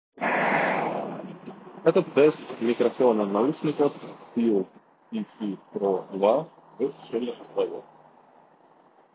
Качество микрофона на 7/10, в шумных условиях не отсекает шум и ловит много лишнего звука. Сам тембр голоса чрезмерно компрессированный.
В шумных условиях:
fiil-cc-pro-2-shum.m4a